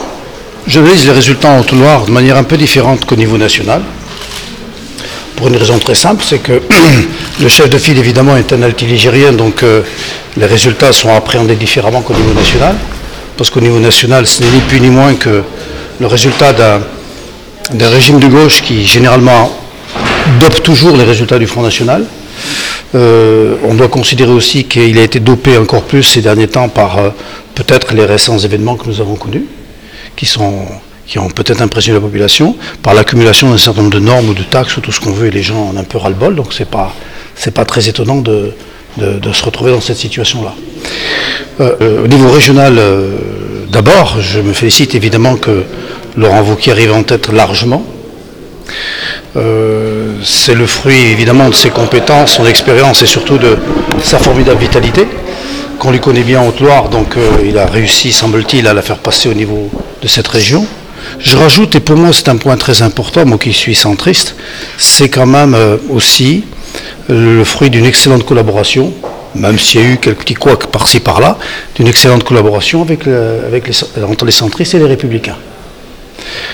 REACTION JEAN PIERRE MARCON PRESIDENT DU CONSEIL DEPARTEMENTAL